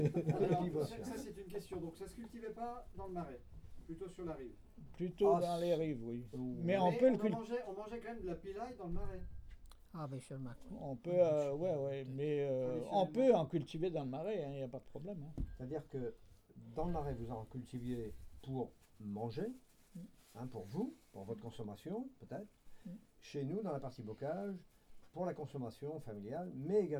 Atelier de collectage de témoignages sur les légumes traditionnels du Marais-Breton-Vendéen
Catégorie Témoignage